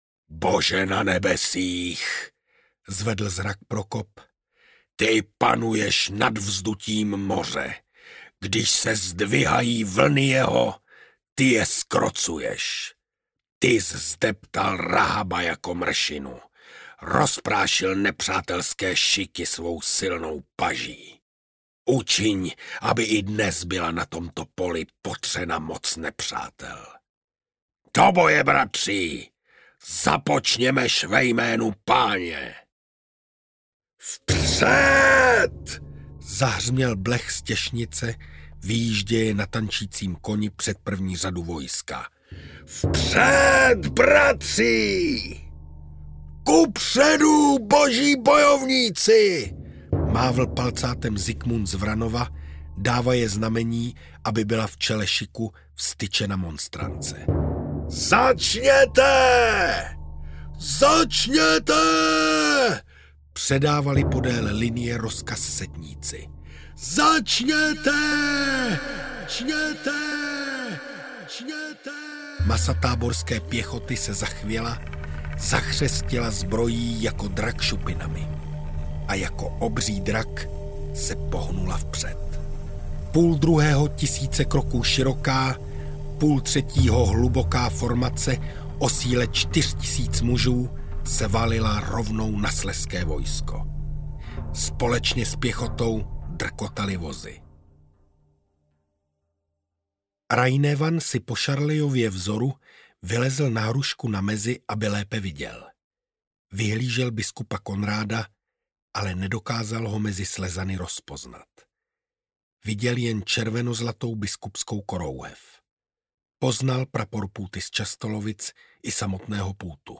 Boží bojovníci audiokniha
Ukázka z knihy
| Vyrobilo studio Soundguru.